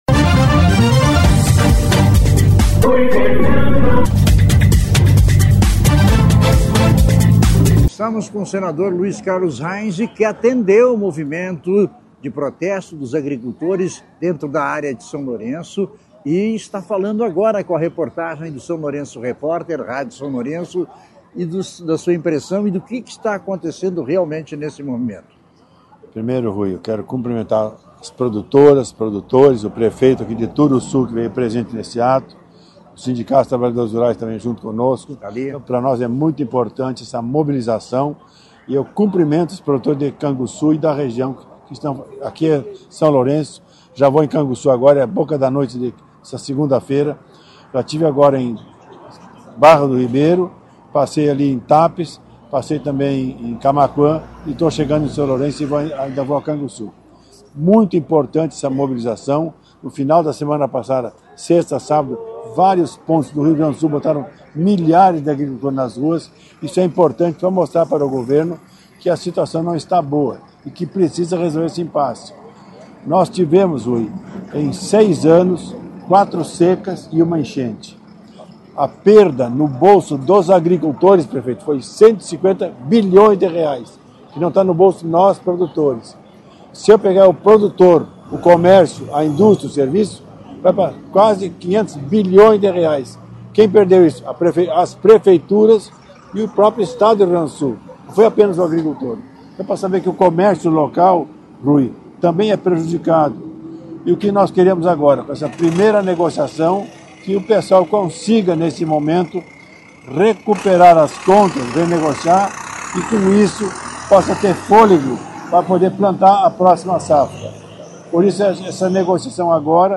MOVIMENTO DOS AGRICULTORES NA BR-116: SLR CONVERSOU COM O SENADOR HEINZE